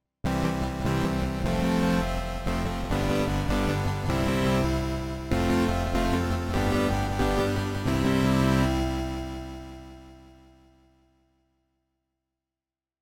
Implemented the "'Colorless' Artificial Reverberation" algorithm described by [Schroeder and Logan '61] Found the paper surprisingly readable.